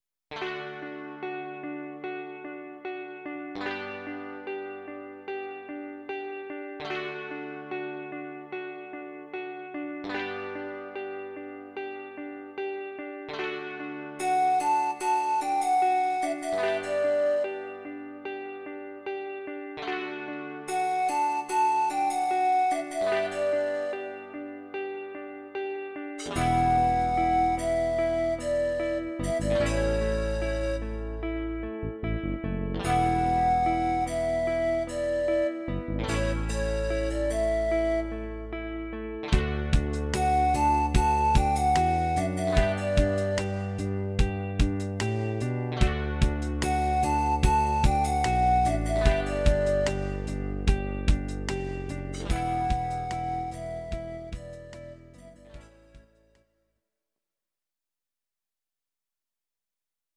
Audio Recordings based on Midi-files
Rock, 1990s